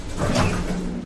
ele_door.ogg